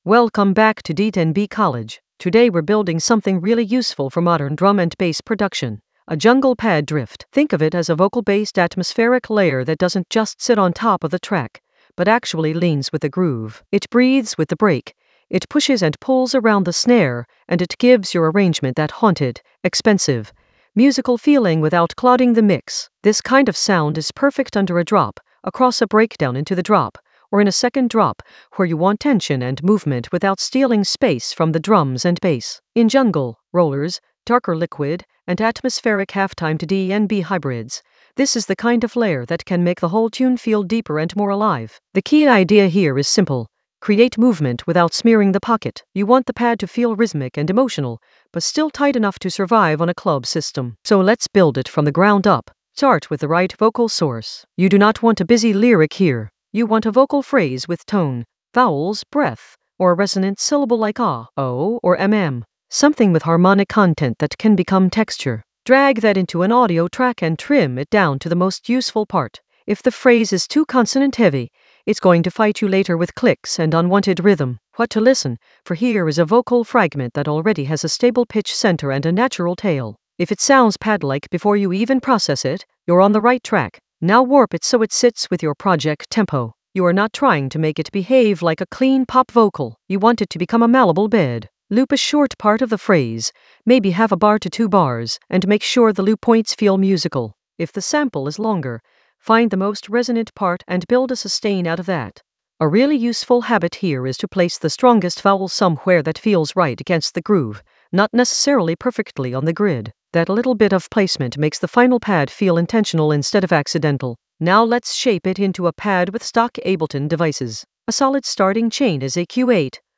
An AI-generated intermediate Ableton lesson focused on Design a jungle pad drift with groove pool tricks in Ableton Live 12 in the Vocals area of drum and bass production.
Narrated lesson audio
The voice track includes the tutorial plus extra teacher commentary.